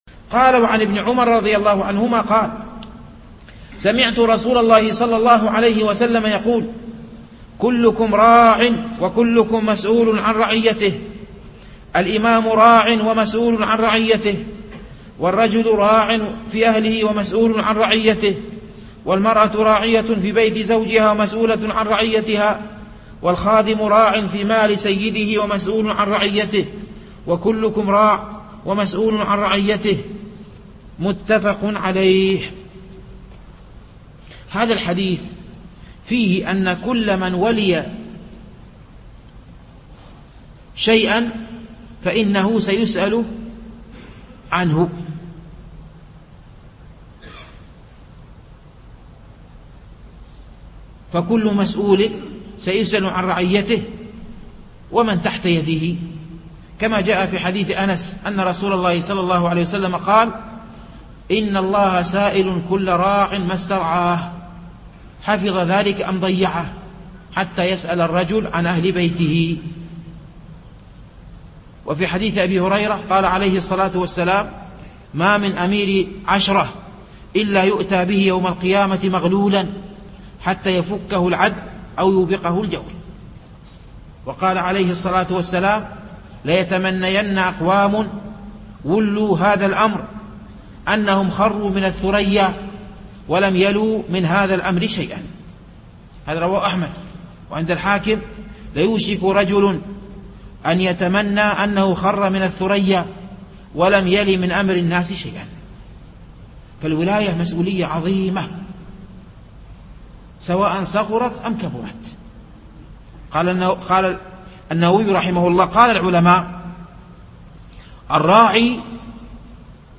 شرح حديث (كلكم راع و كلكم مسؤول عن رعيته)